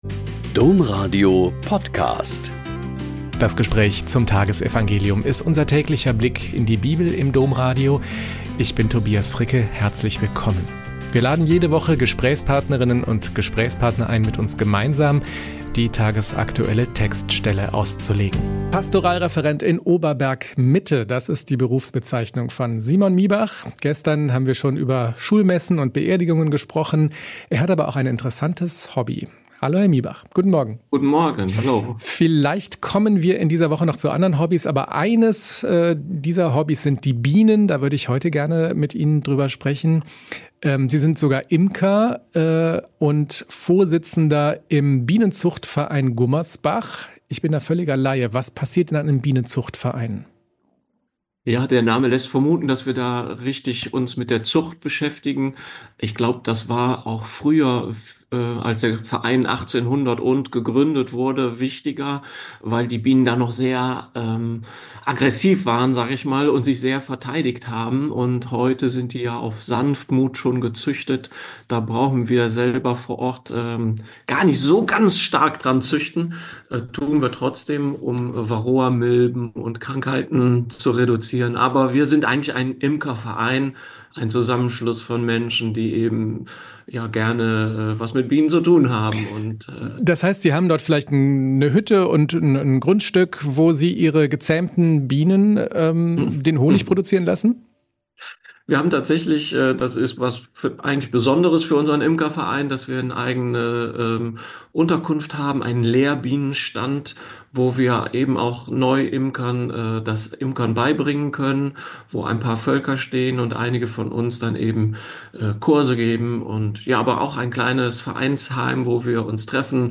Mt 1,1-17 - Gespräch